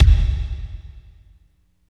30.05 KICK.wav